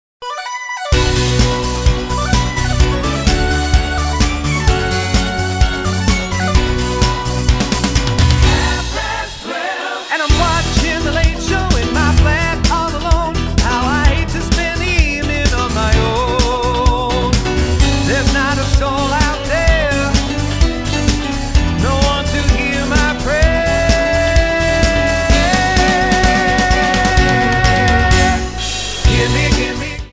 --> MP3 Demo abspielen...
Tonart:Dbm-Eb-Dbm-C-G-B-E) Multifile (kein Sofortdownload.
Die besten Playbacks Instrumentals und Karaoke Versionen .